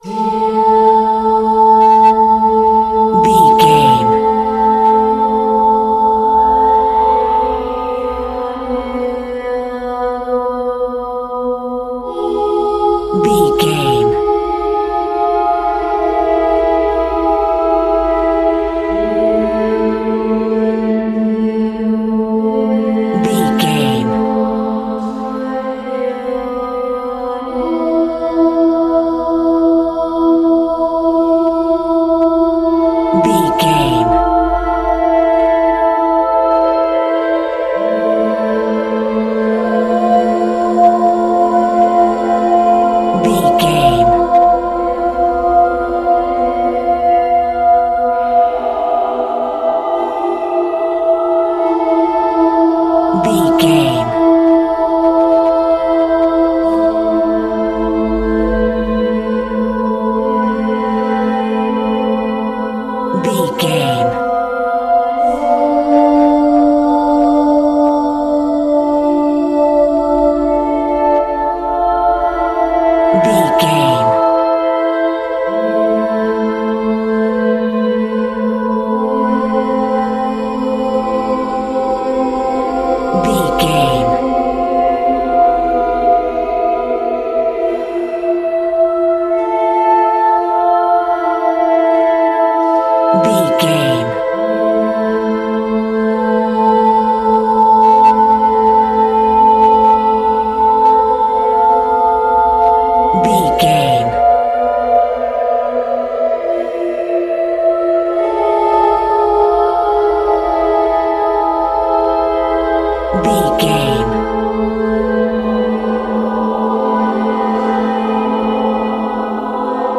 Dark Voices.
Aeolian/Minor
Slow
scary
tension
ominous
haunting
eerie
sythesizer
horror
Eerie Voices